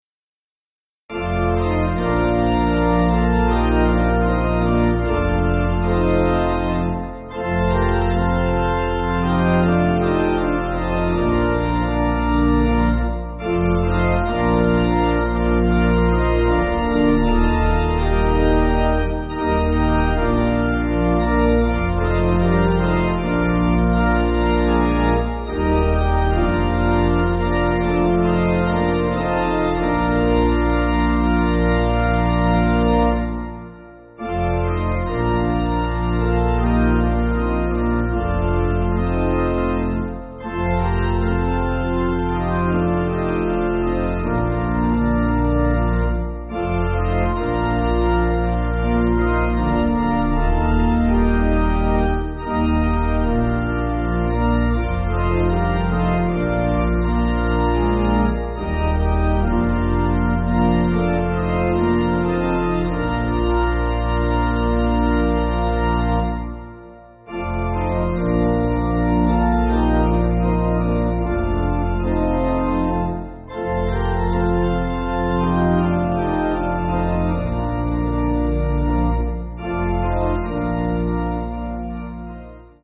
Organ
(CM)   5/Bb
4/4 Time